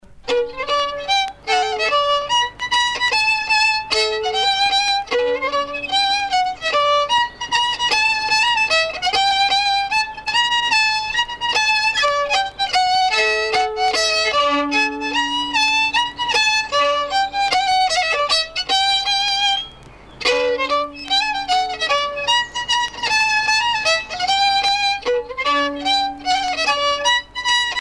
danse : mazurka
Pièce musicale éditée